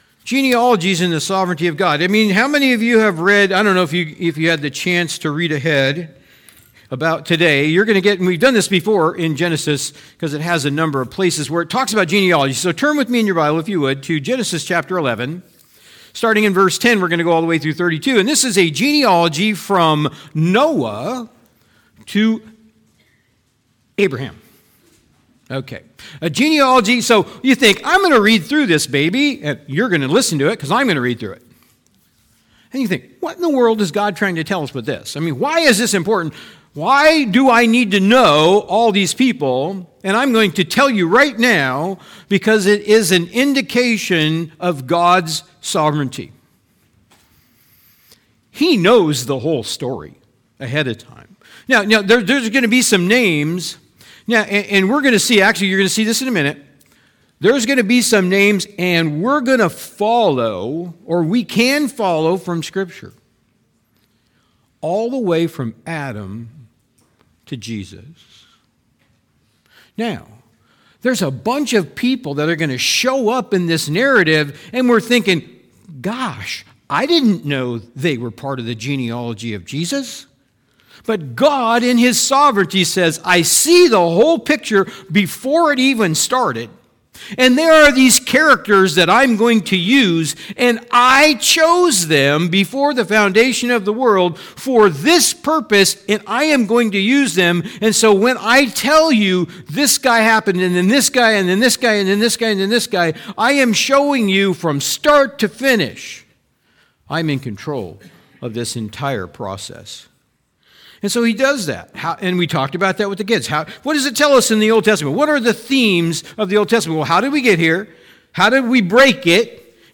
Sermons | Machias Community Church